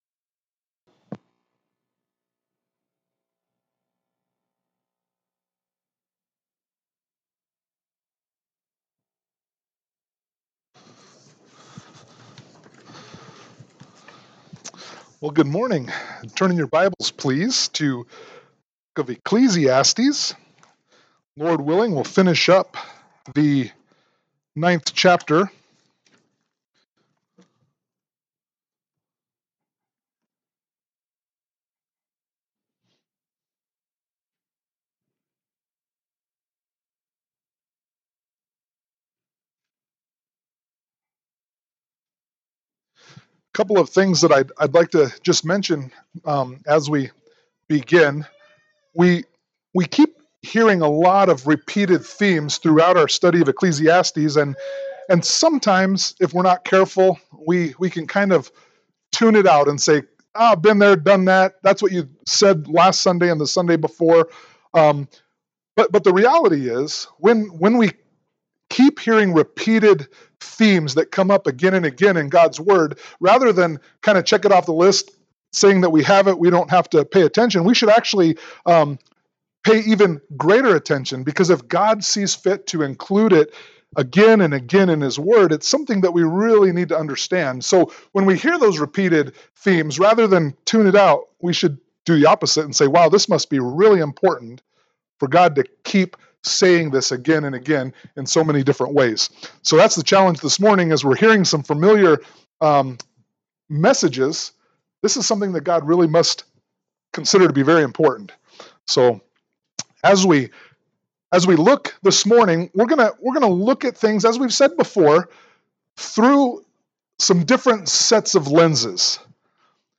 Ecclesiastes 9:11-18 Service Type: Sunday Morning Worship « Ecclesiastes 9:1-10 Ecclesiastes Chapter 10